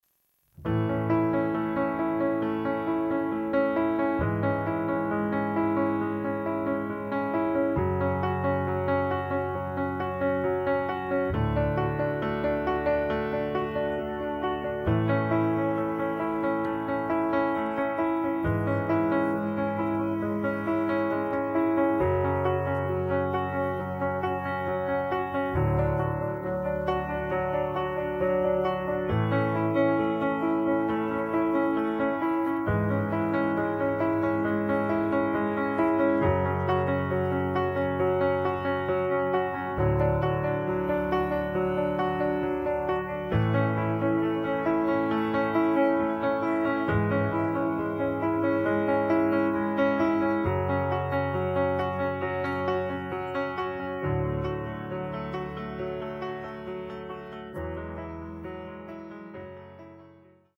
음정 원키